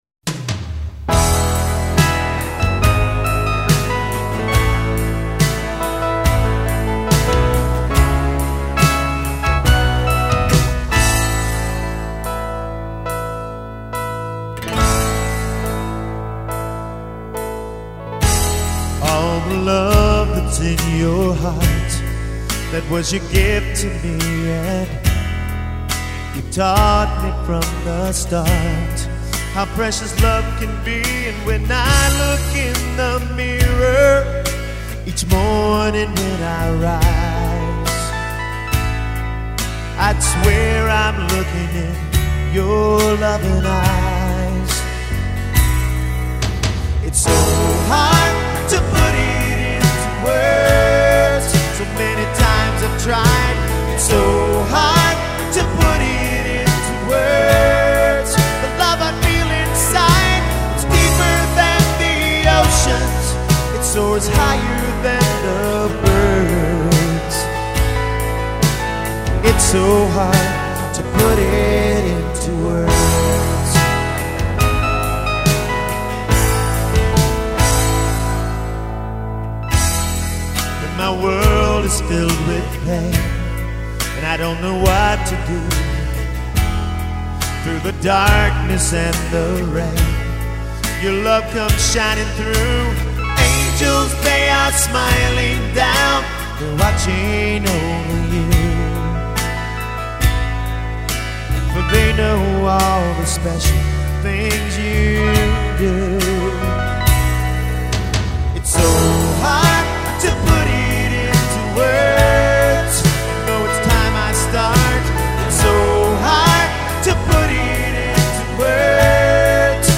Lead and Background Vocals, Acoustic Guitar
Keyboards, Synth Bass, Drum Programming